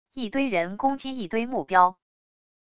一堆人攻击一堆目标wav音频